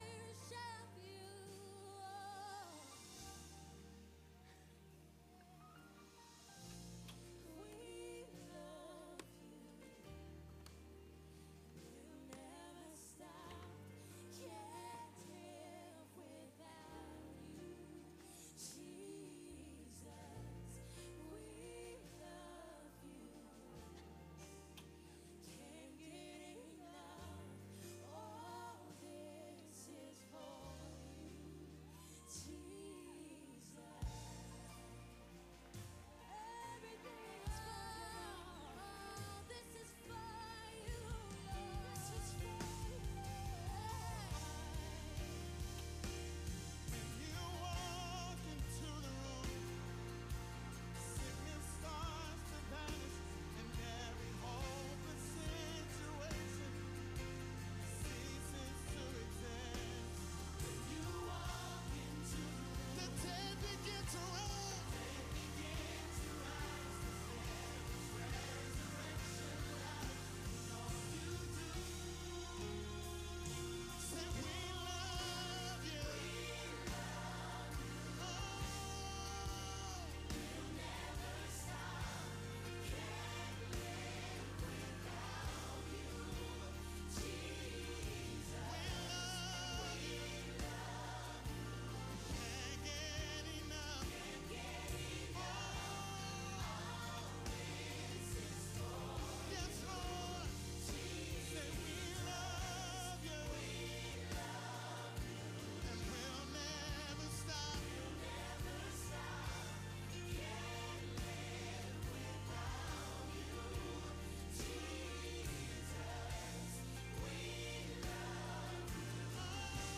Grace Church Wednesday Service. The Truth About The Rapture.